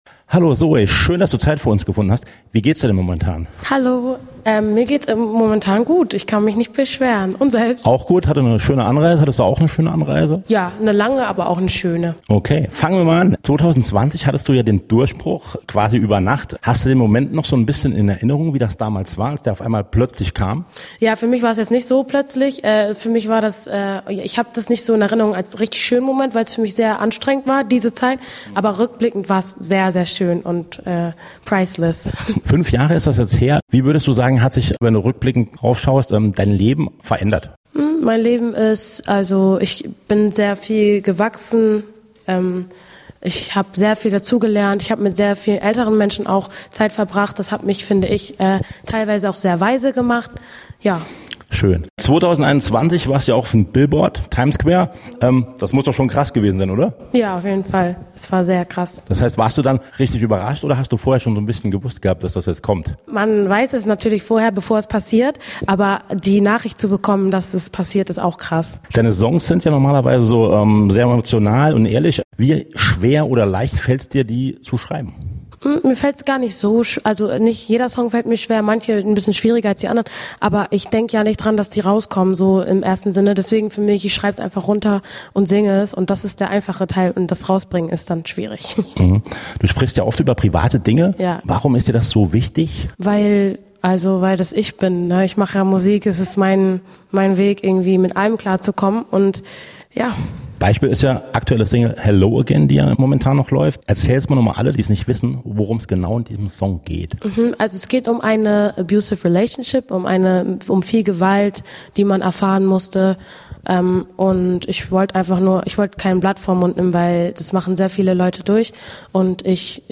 Zoe Wees im Gespräch mit Radio Salü: Ehrlich, emotional und voller Energie!